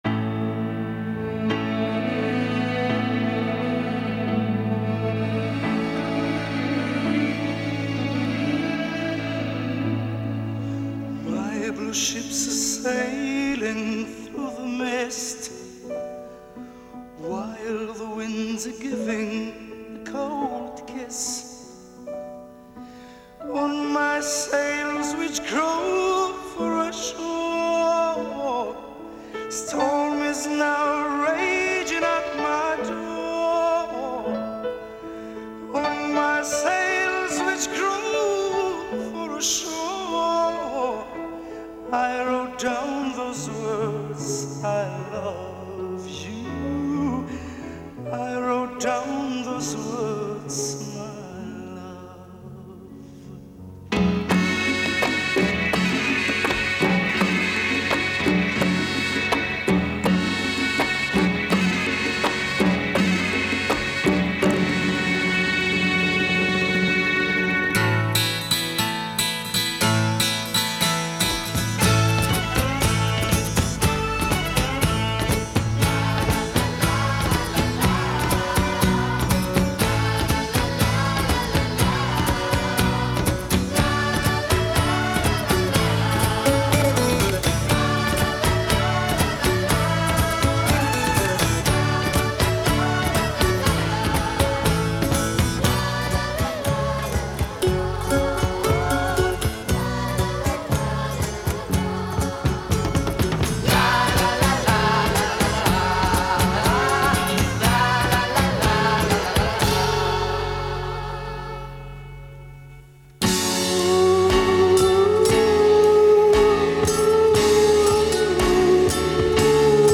поп-музыка с ярко выраженным средиземноморским акцентом